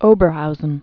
(ōbər-houzən)